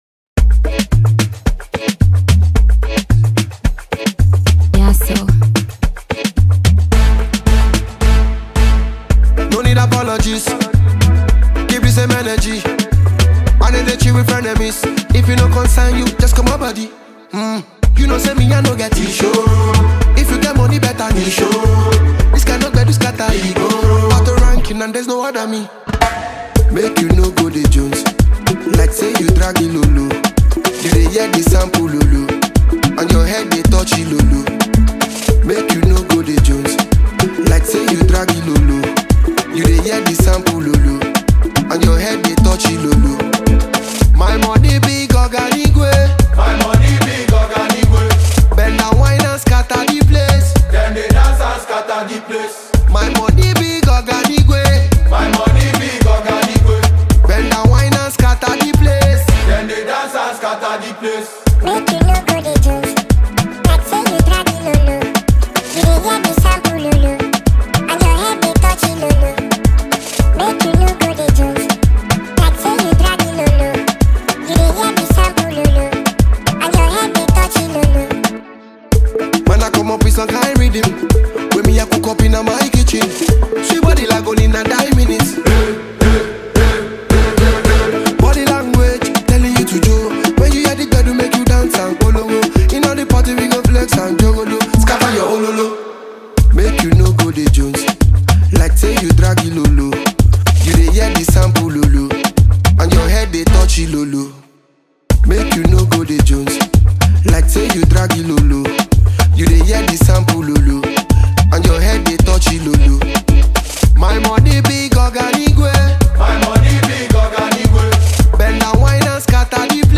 Afro-dancehall
is a captivating tune packed with rhythm, energy